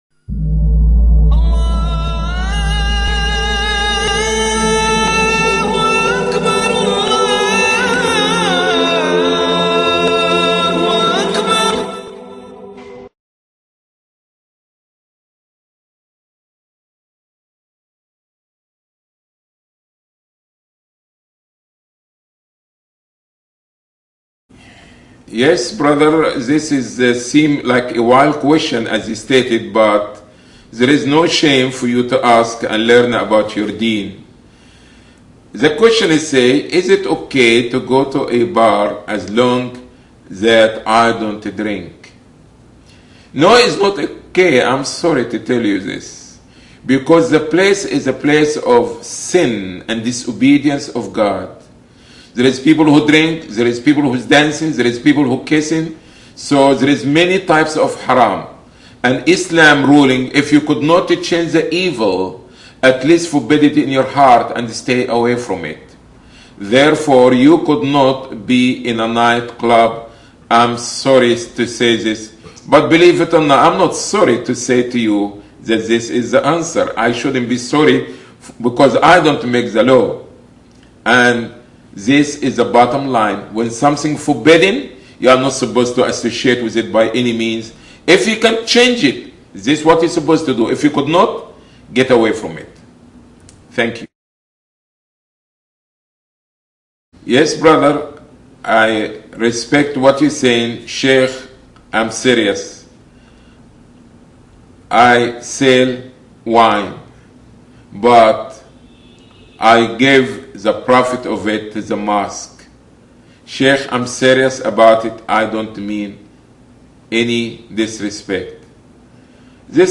Two further questions from the audience touched on matters that, on the surface, appear rooted in love and longstanding cultural practice — but which carry the most serious implications in Islamic theology.